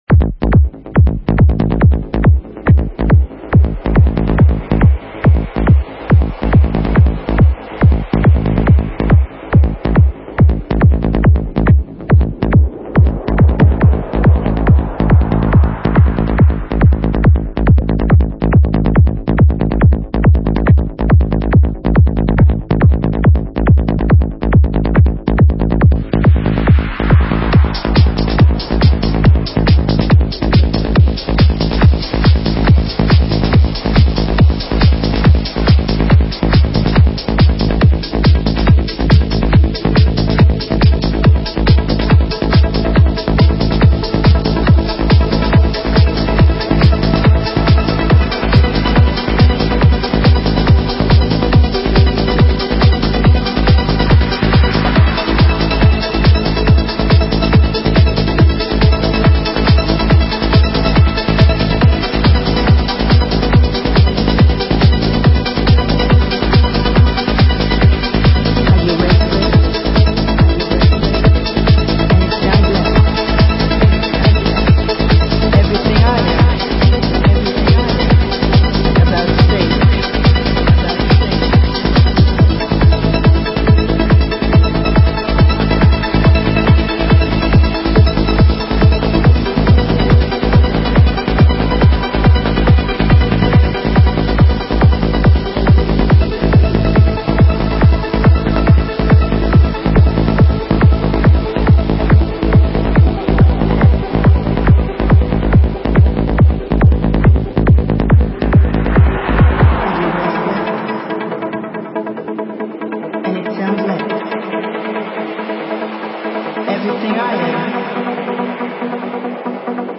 Назад в Progressive Trance
Style: Psy-Prog
Quality: VBR V0 / Joint Stereo